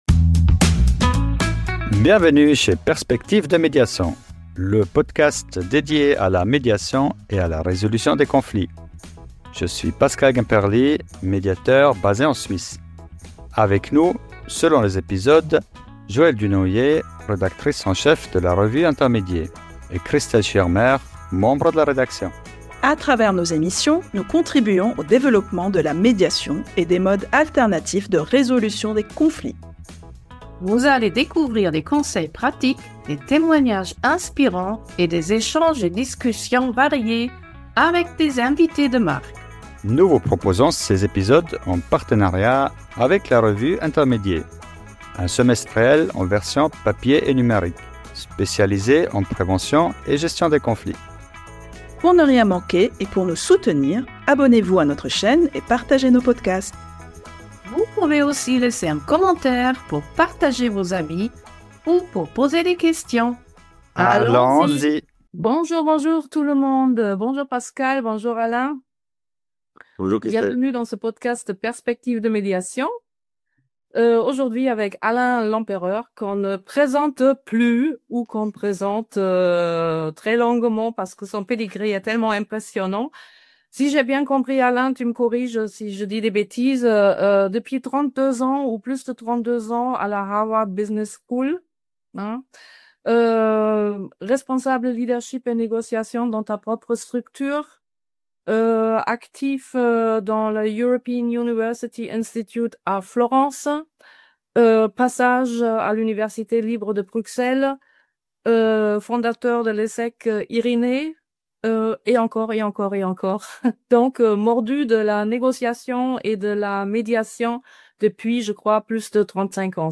📝 Dans cet épisode captivant de Perspectives de Médiation, nous recevons l’incontournable Alain Lempereur, expert international en négociation et médiation, pour parler de son ouvrage Méthode de médiation 🕊.